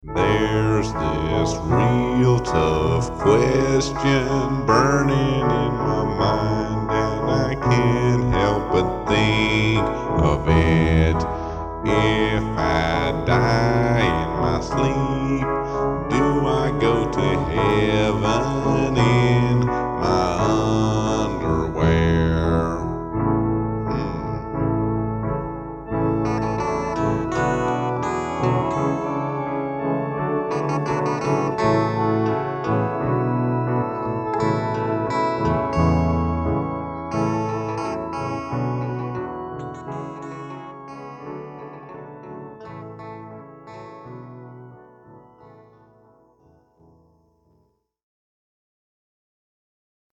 It’s short but catchy.